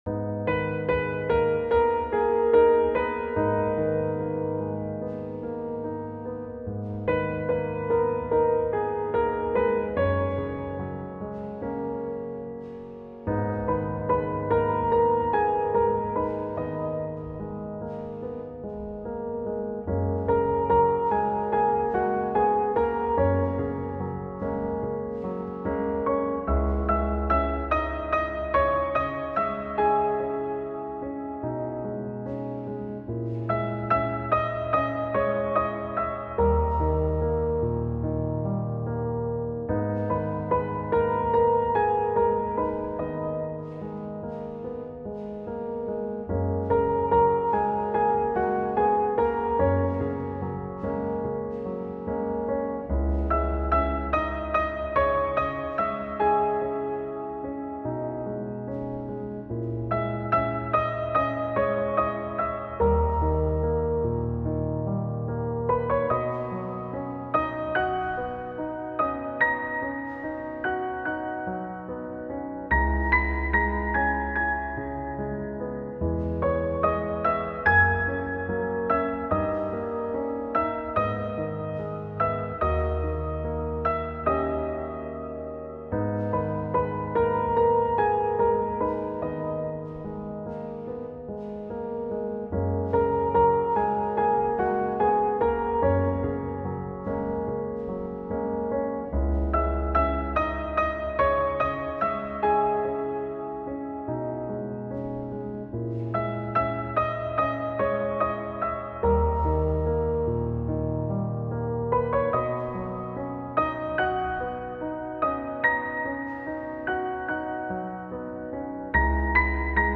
آهنگ ارامش بخش
آهنگ مخصوص مدیتیشن آهنگ با پیانو
سولو پیانو